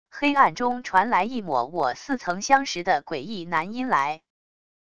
黑暗中传来一抹我似曾相识的诡异男音来wav音频